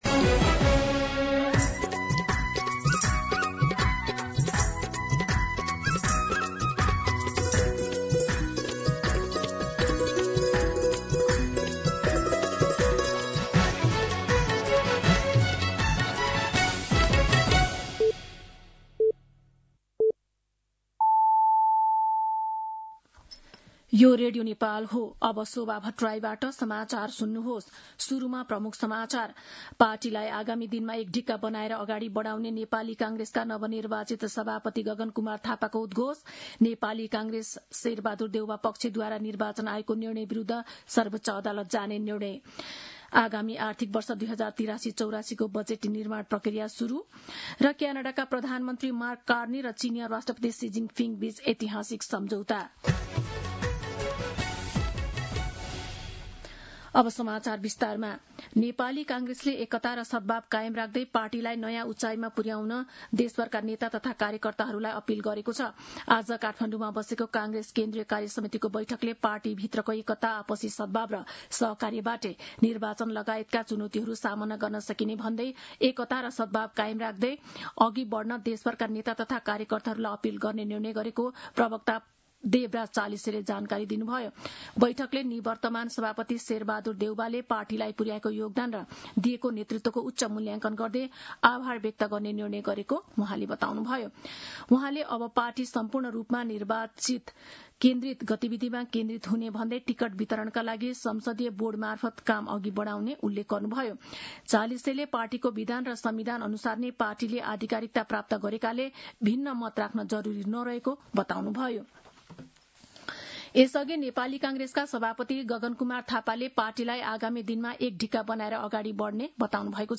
दिउँसो ३ बजेको नेपाली समाचार : ३ माघ , २०८२
3pm-Nepali-News-2.mp3